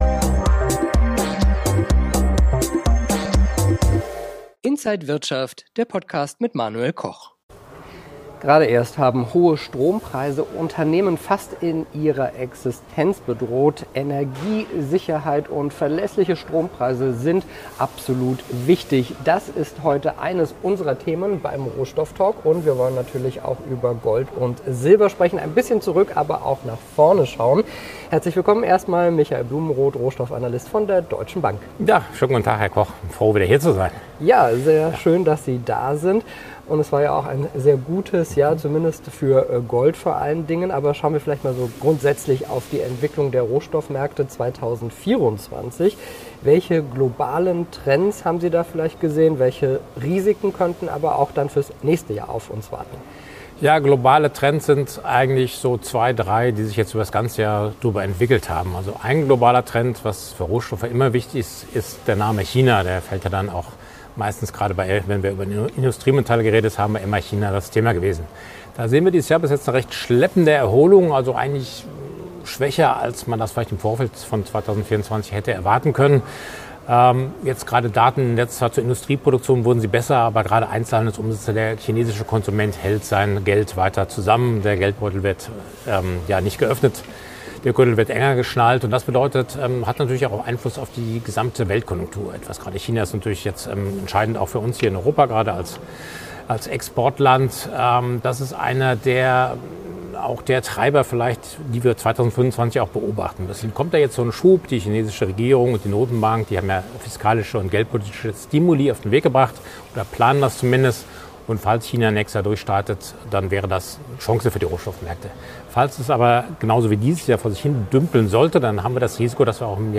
Rohstoff-Talk